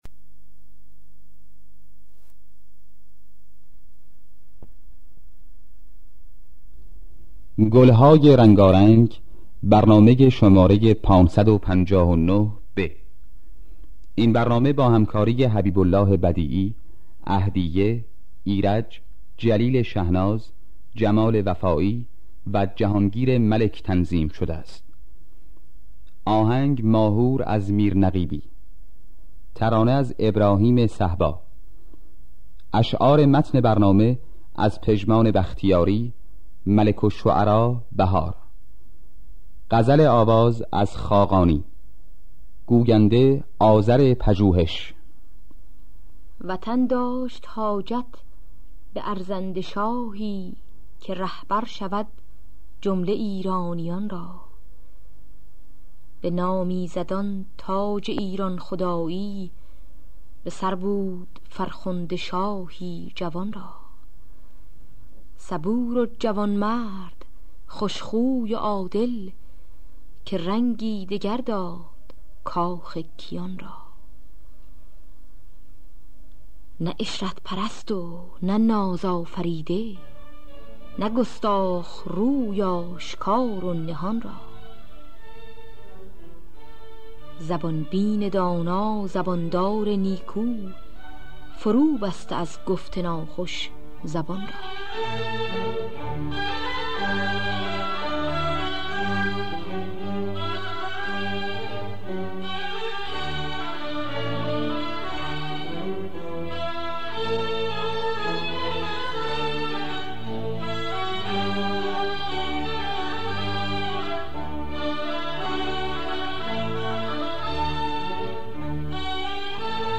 دانلود گلهای رنگارنگ ۵۵۹ب با صدای جمال وفایی، عهدیه، ایرج در دستگاه ماهور. آرشیو کامل برنامه‌های رادیو ایران با کیفیت بالا.